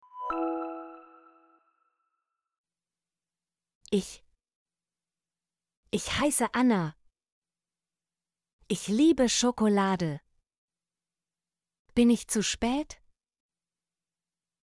ich - Example Sentences & Pronunciation, German Frequency List